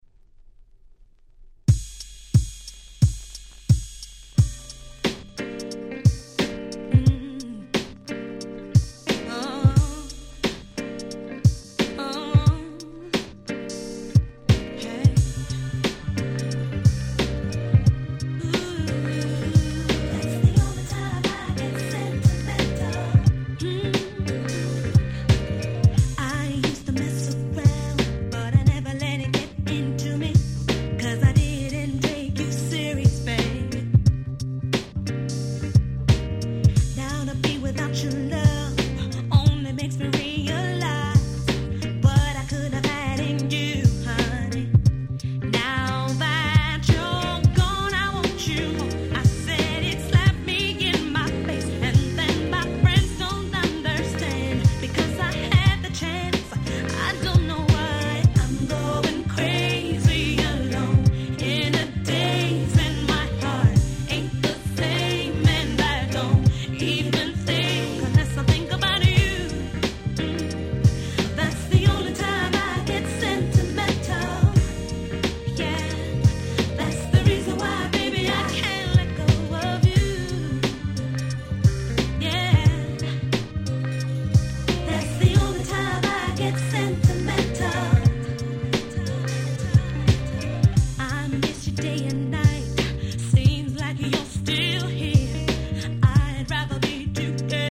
95' Super Hit R&B !!